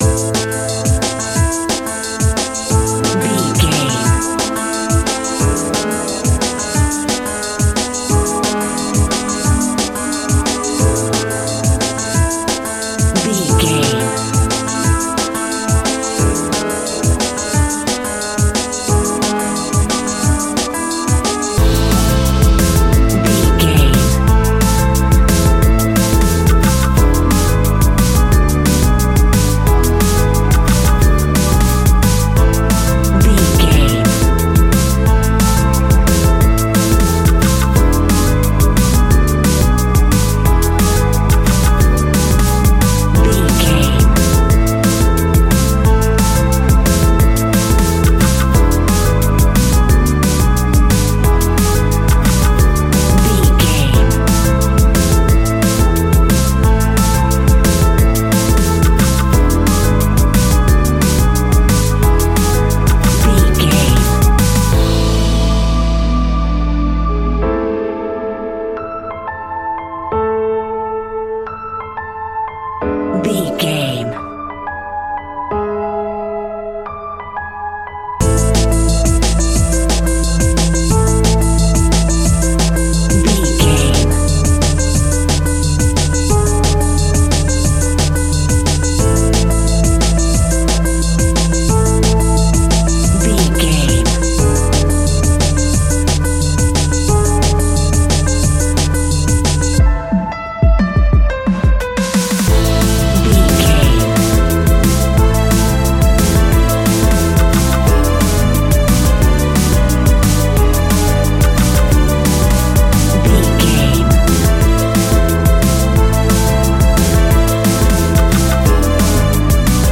Aeolian/Minor
Fast
frantic
driving
energetic
dramatic
groovy
piano
bass guitar
drums
drum machine
synthesiser
strings
electronic
instrumentals
synth bass
synth lead
robotic